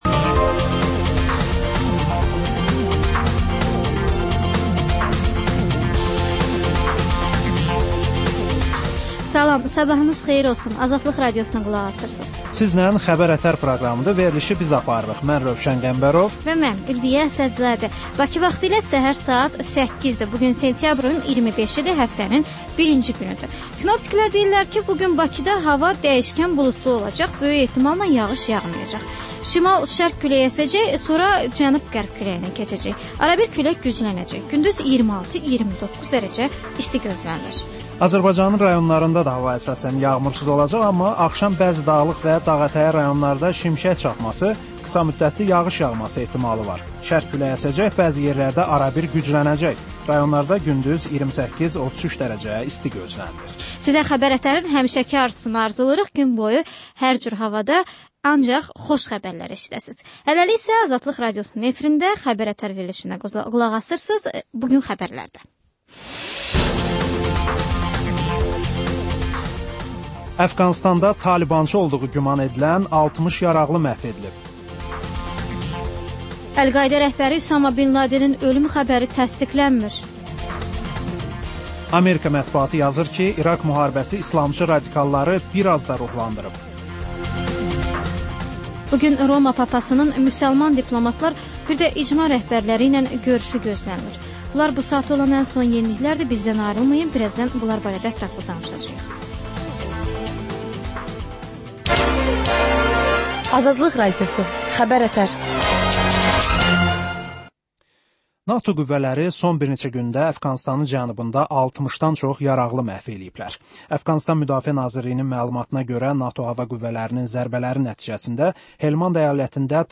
Səhər-səhər, Xəbər-ətər: xəbərlər, reportajlar, müsahibələr İZ: Mədəniyyət proqramı. Və: Tanınmışlar: Ölkənin tanınmış simalarıyla söhbət.